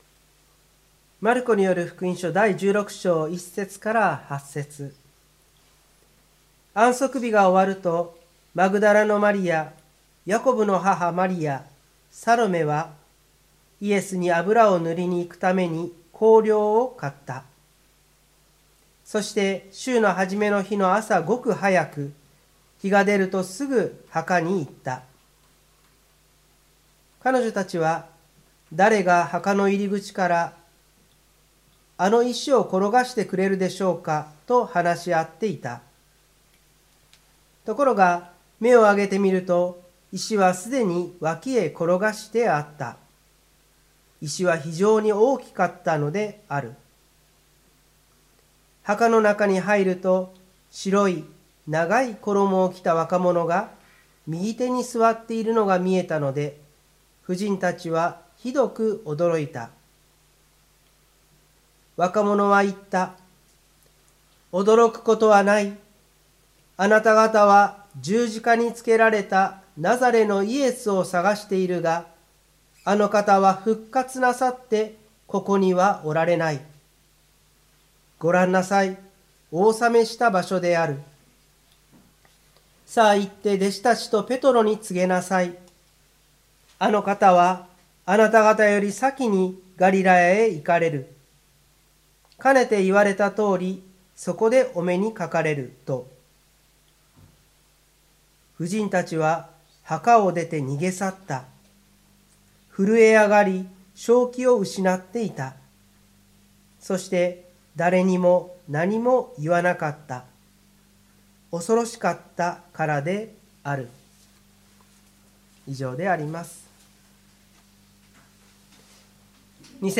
湖北台教会の礼拝説教アーカイブ。